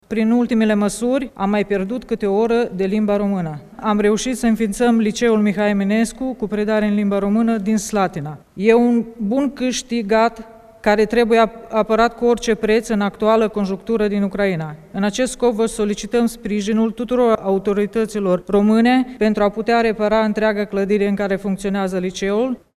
Șeful statului i-a decorat ieri la Cotroceni pe foștii și actualii profesori de Română care predau la școlile și în universitățile din străinătate.
O profesoară din regiunea Transcarpatia a vorbit de dificultățile românilor din Ucraina pentru a învăța în limba maternă.
30aug-16-profesoara-din-Ucraina.mp3